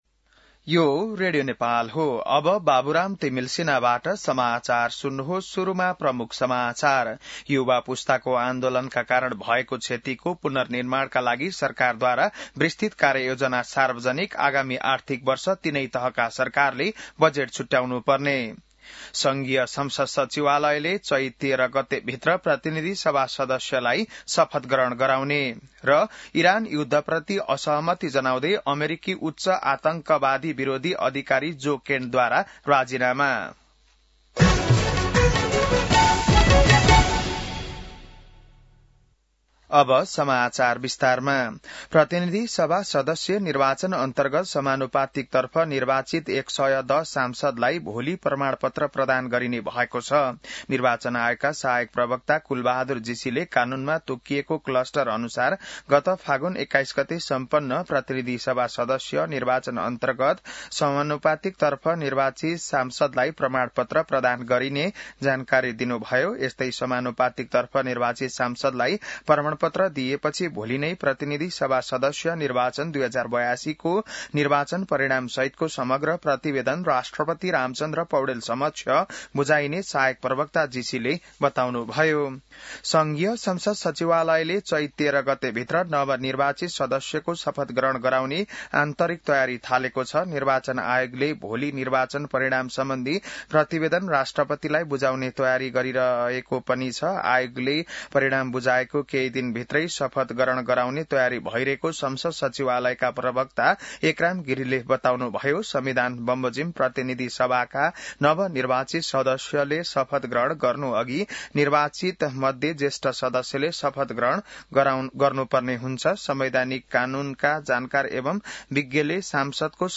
बिहान ९ बजेको नेपाली समाचार : ४ चैत , २०८२